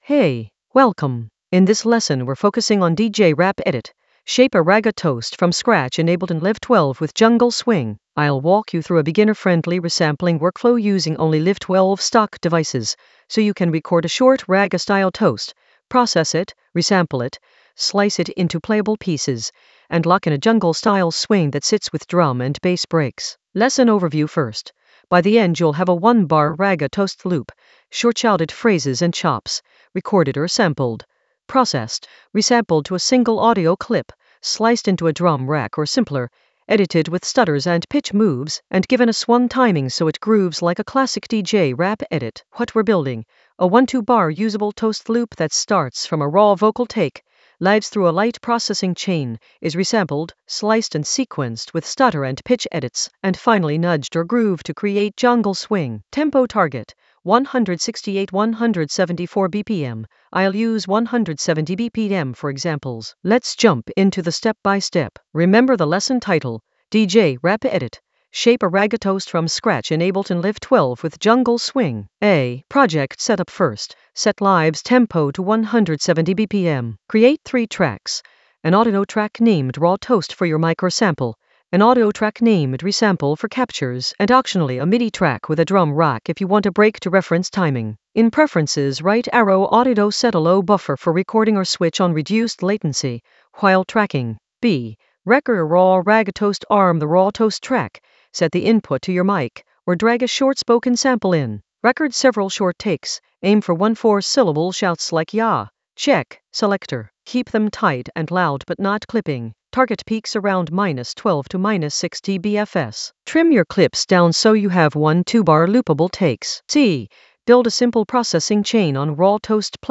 An AI-generated beginner Ableton lesson focused on DJ Rap edit: shape a ragga toast from scratch in Ableton Live 12 with jungle swing in the Resampling area of drum and bass production.
Narrated lesson audio
The voice track includes the tutorial plus extra teacher commentary.